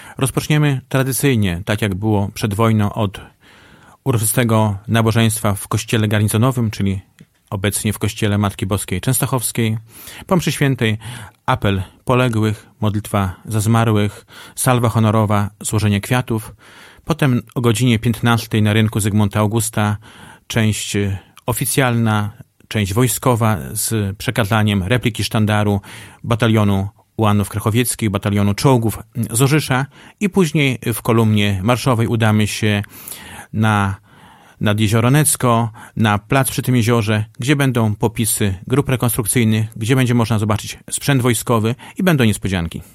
O szczegółach mówił we wtorek (18.07) w Radiu 5 Jarosław Szlaszyński, starosta powiatu augustowskiego.